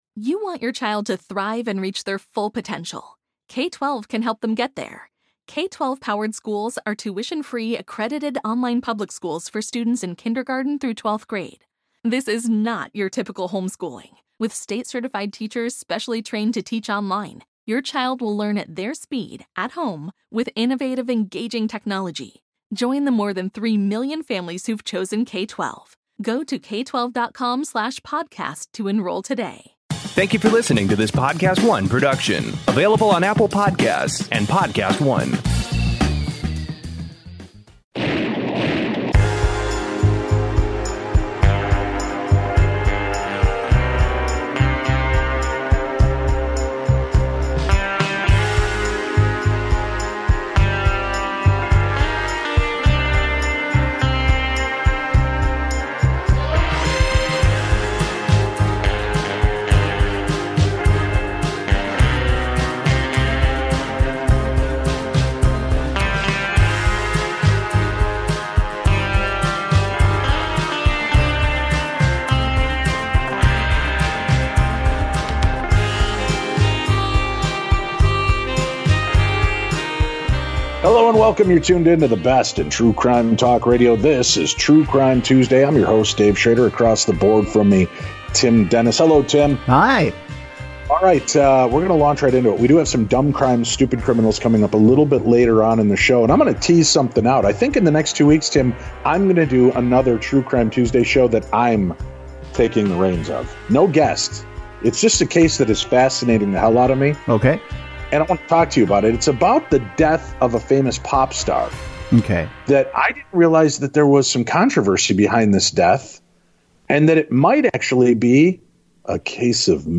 true crime talk radio